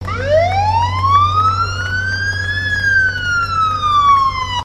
دانلود آهنگ آژیر 6 از افکت صوتی حمل و نقل
دانلود صدای آژیر 6 از ساعد نیوز با لینک مستقیم و کیفیت بالا
برچسب: دانلود آهنگ های افکت صوتی حمل و نقل دانلود آلبوم صدای آژیر خطر از افکت صوتی حمل و نقل